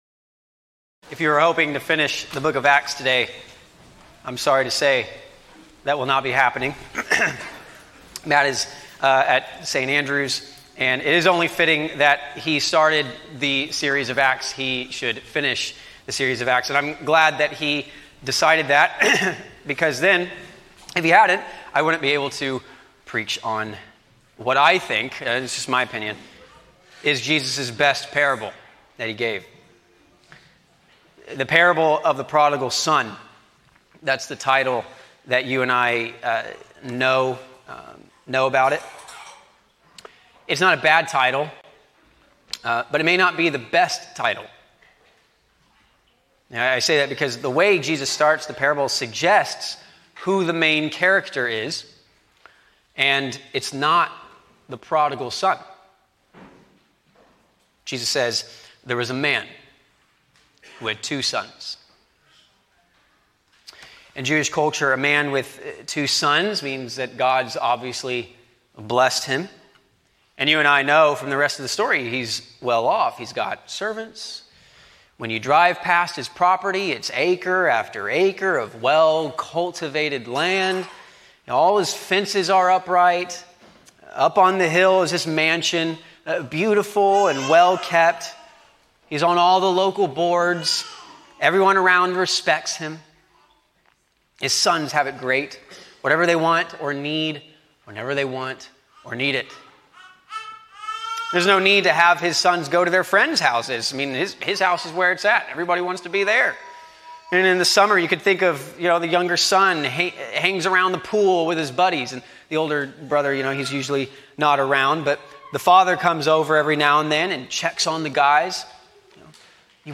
A sermon on Luke 15:11-32